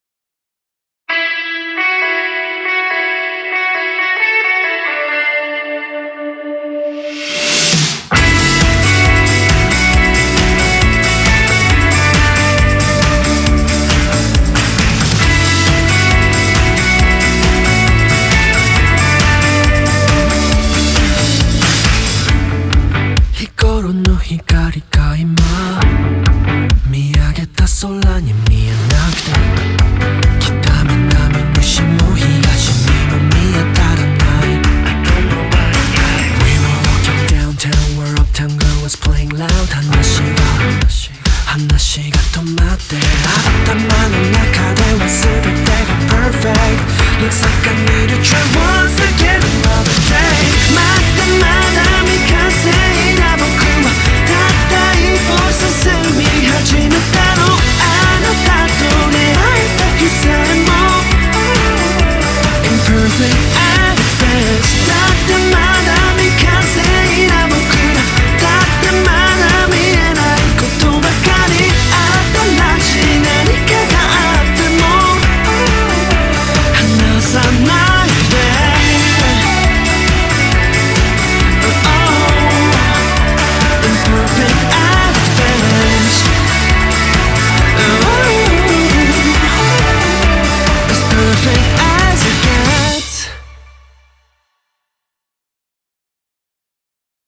BPM136
Audio QualityMusic Cut